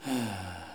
ah-soulagement_05.wav